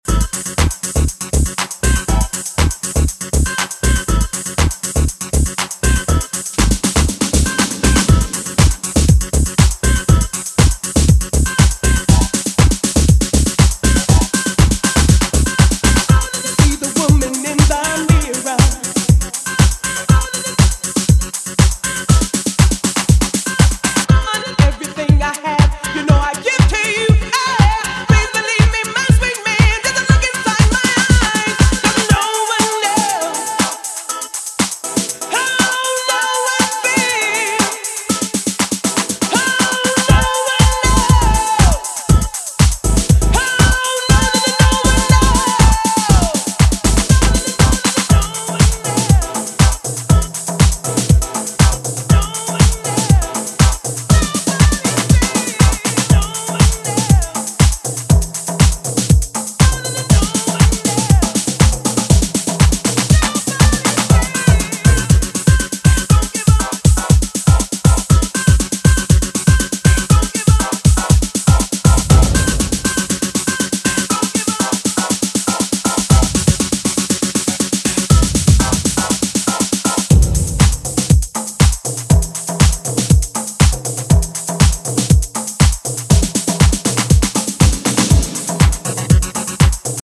ジャンル(スタイル) DEEP HOUSE / GARAGE HOUSE